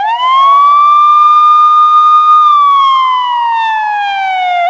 emv_wail.wav